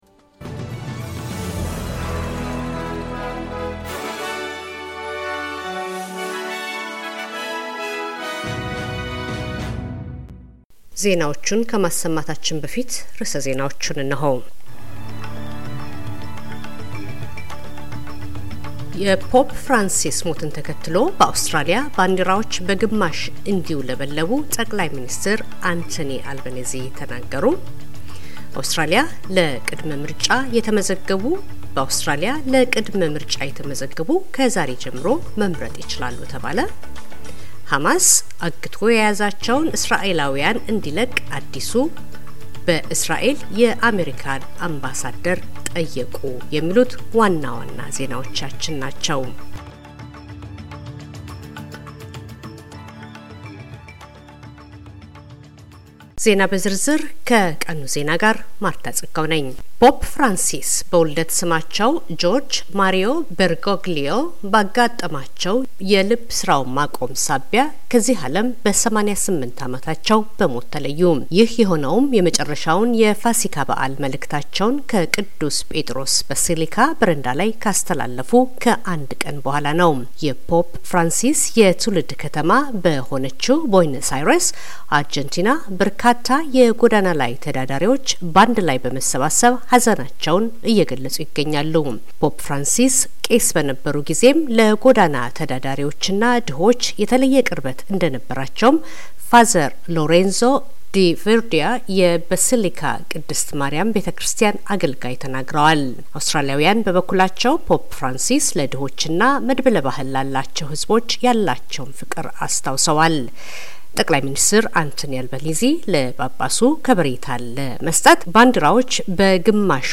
ዜና - የፖፕ ፍራንሲስን ከዚህ አለም በሞት መለየትን ተከተሎ በአውስትራሊያ ባንዲራዎች ከግማሽ በታች እንዲውለበለቡ ጠቅማይ ሚ/ር አንቶኒ አልበኒዚ አዘዙ